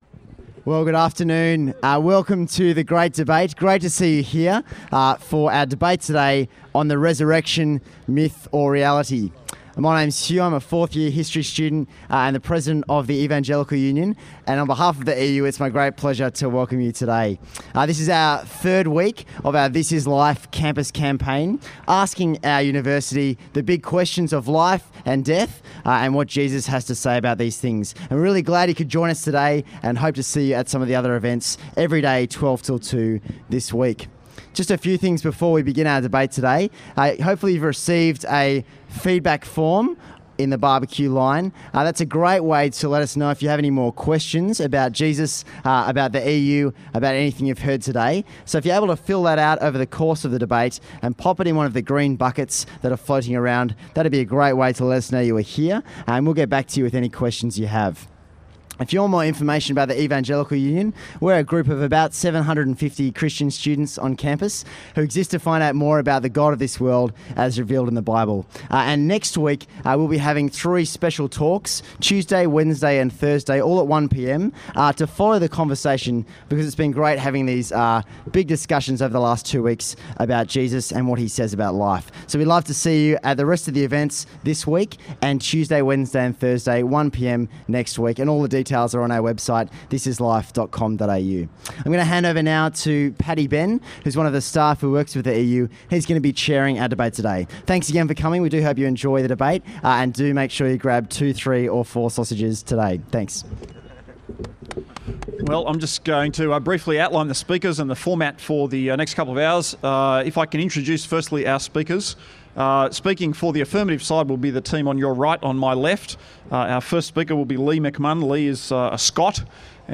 Evidence For the Resurrection (Part 1) Author: William Lane Craig Date: Sunday, 11 August 2013 Tags: Resurrection Description: William Lane Craig spoke to a full house in The Great Hall, University of Sydney, on the topic of Evidence for the Resurrection. This is the first hour, recorded between 12-1pm.